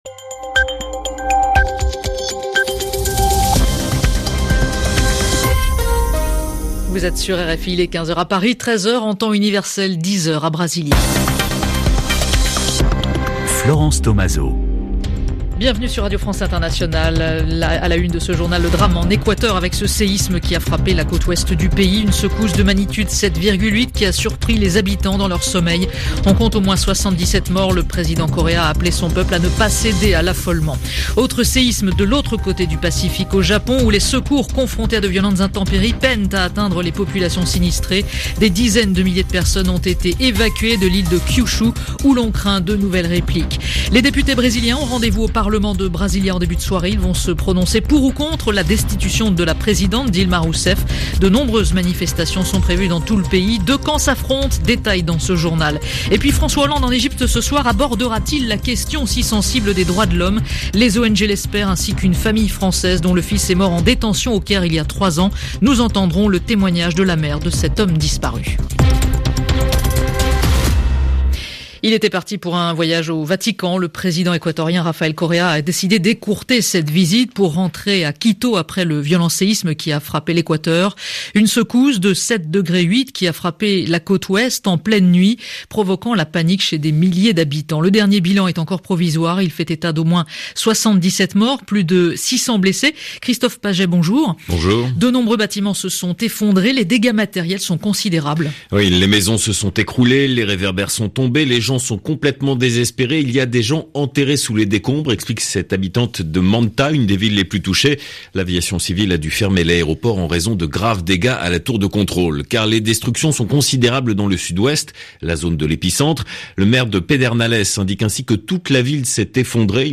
I was interviewed for a broadcast by Radio France International in Paris.